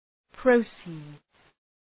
{‘prəʋsıdz}